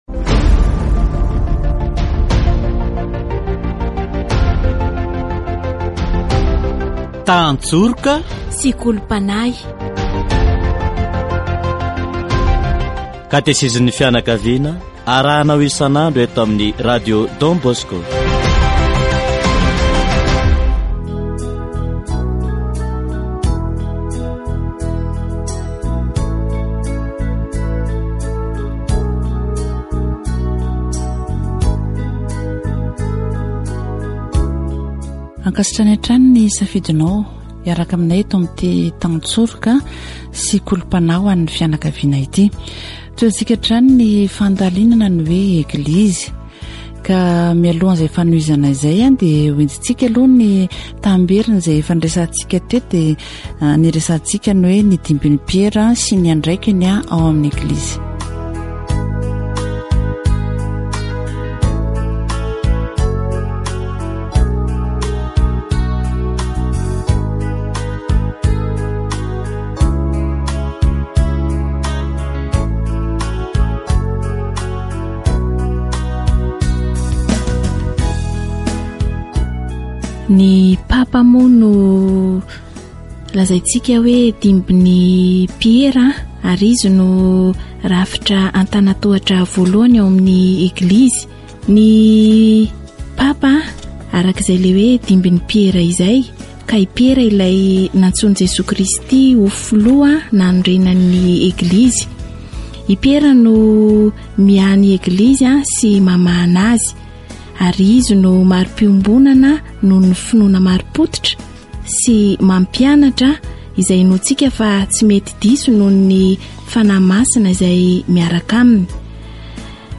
Catéchèse sur l'Eglise